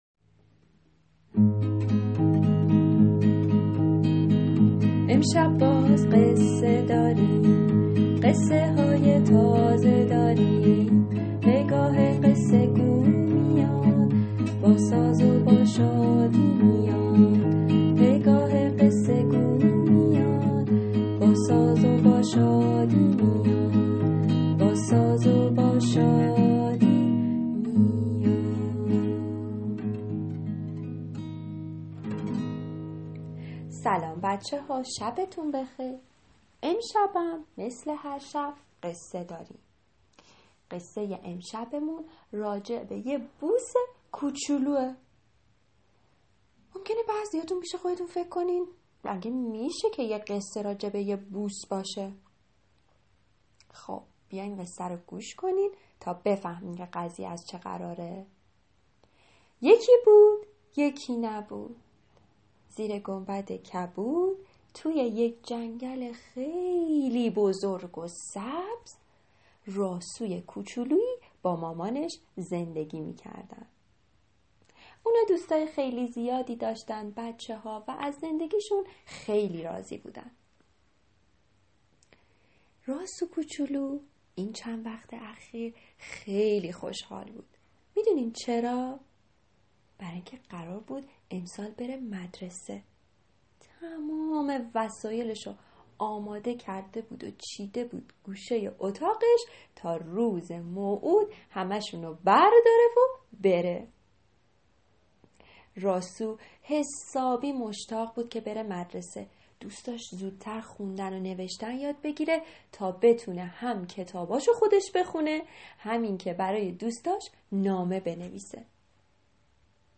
قصه کودکانه صوتی بوسه ی راسو